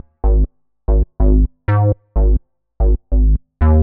cch_bass_loop_jock_125_G#m.wav